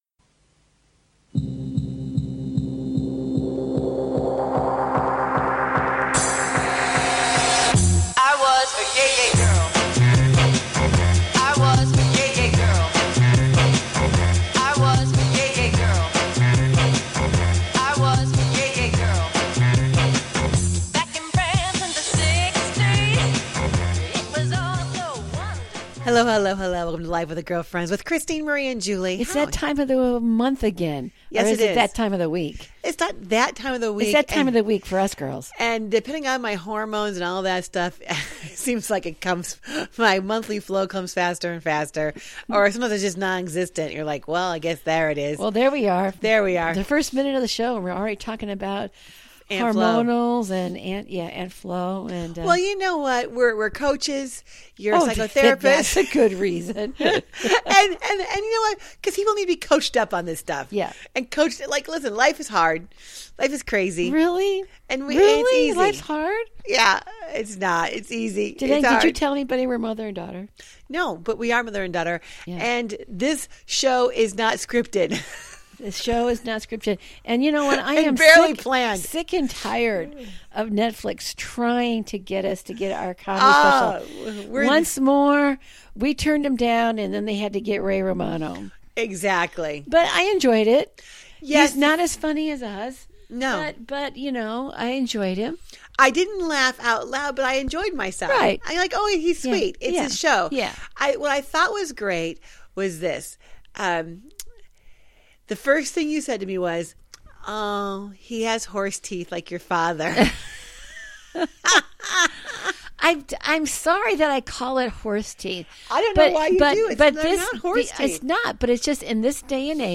They welcome a wide range of guest to their den for some juicy conversation.